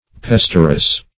Search Result for " pesterous" : The Collaborative International Dictionary of English v.0.48: Pesterous \Pes"ter*ous\, a. Inclined to pester.